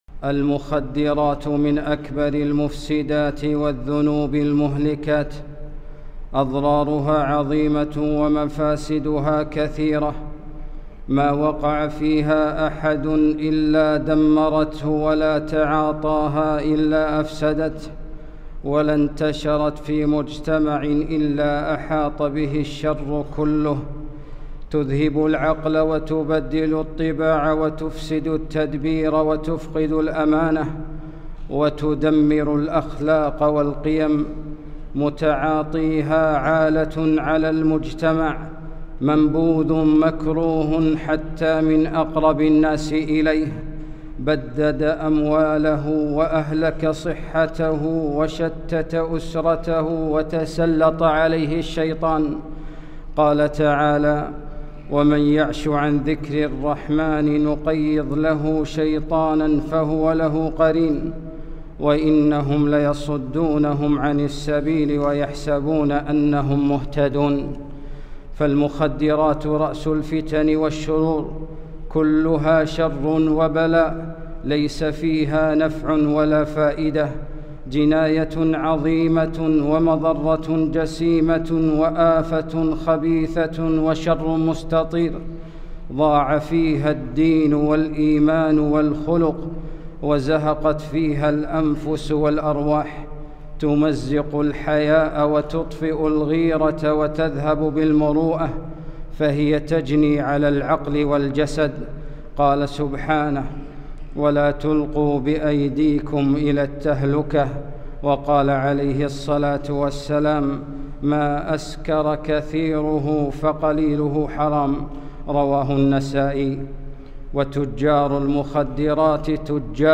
خطبة - المخدرات